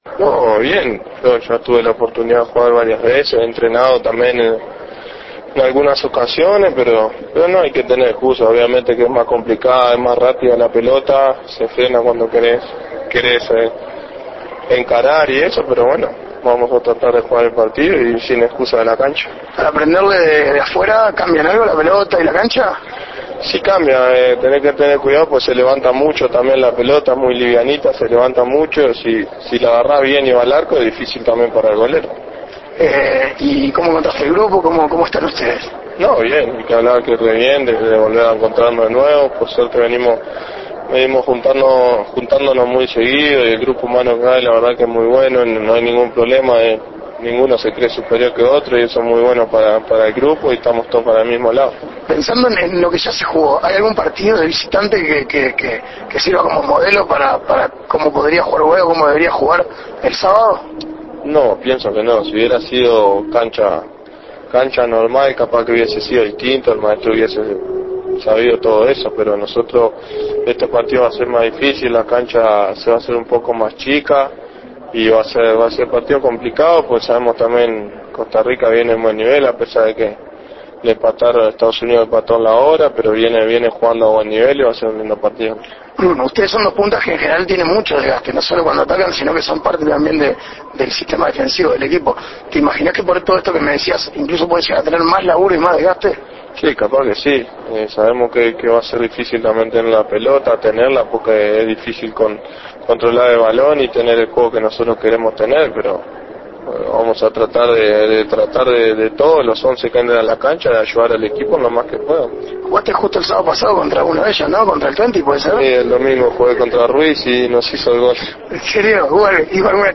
A muy poco del gran desafío ante Costa Rica, el delantero Luis Suárez dialogó con 13a0 y contó sus sensaciones de cara el encuentro del sábado.